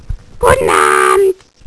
Die vond ze vroeger op TV altijd zo leuk, vanwege de grappige tekenfilmpjes en de verwelkoming: